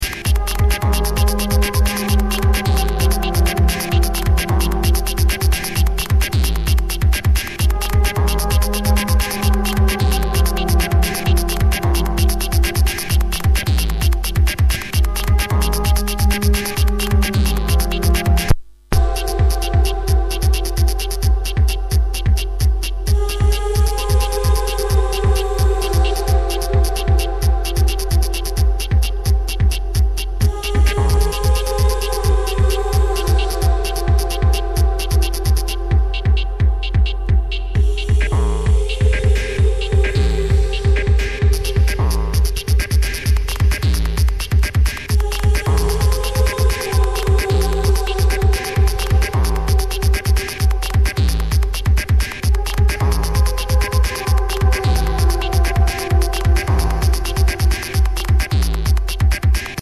Electronix